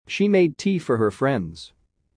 【ややスロー・スピード】